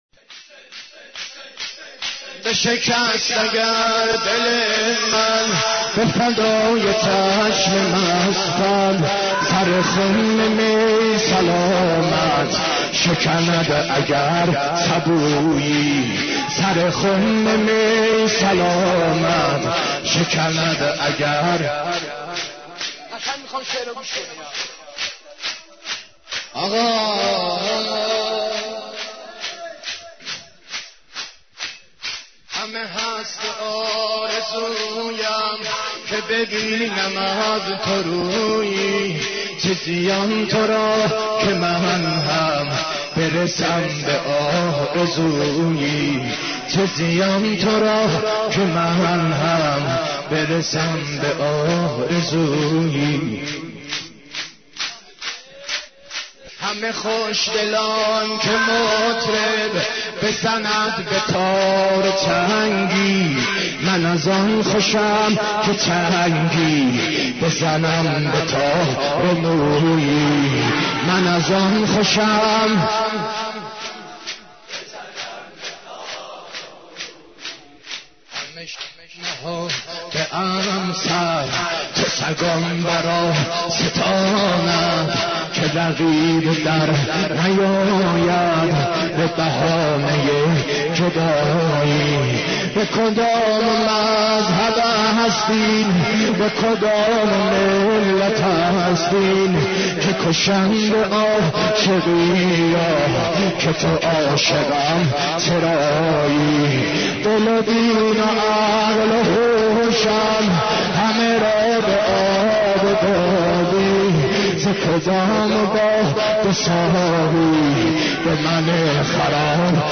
حضرت عباس ع ـ شور 32